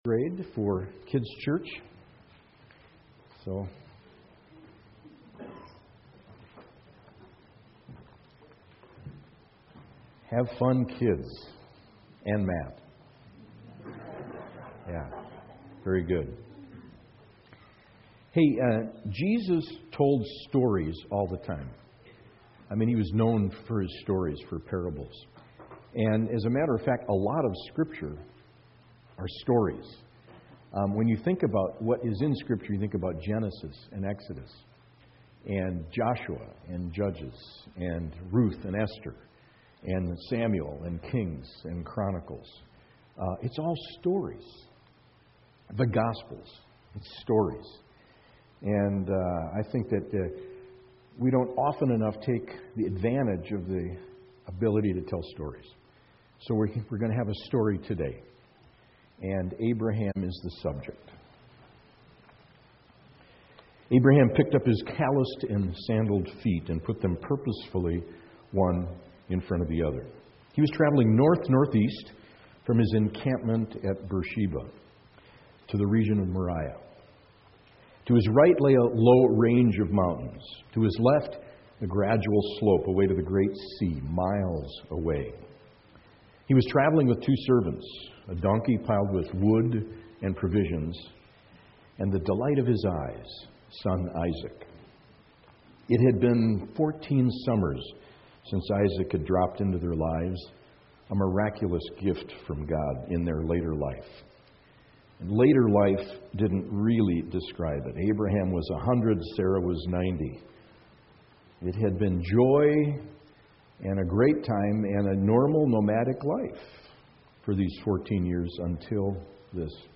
A Series in Hebrews Service Type: Worship Service Preacher